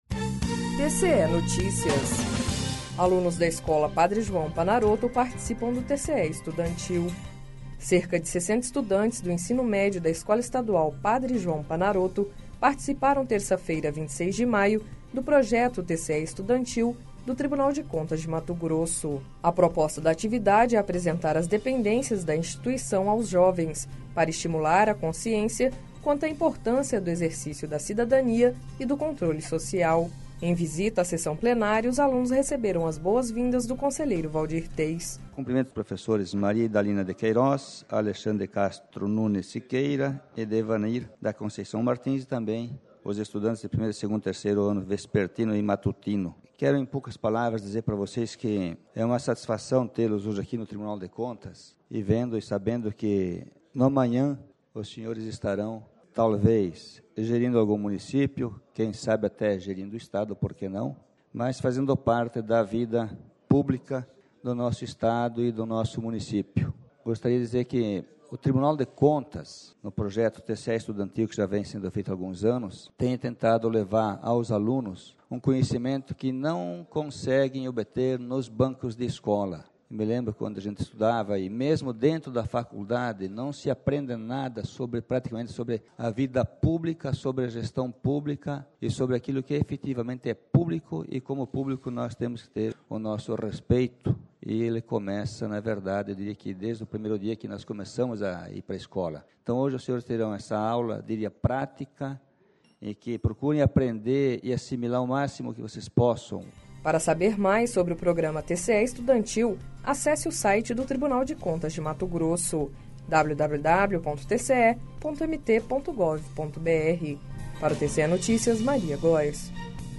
Sonora: Waldir Teis - conselheiro do TCE-MT